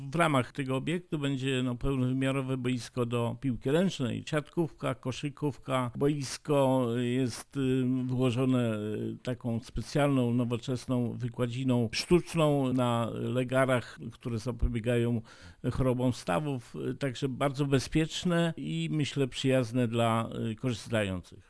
Wójt Jan Czyżewski uważa, że nawet w tak niewielkiej gminie jak Wojciechów, taki obiekt jest potrzebny. Chociaż budynek powstał przy miejscowym zespole szkół, będą z niego korzystać nie tylko uczniowie, ale także inni mieszkańcy: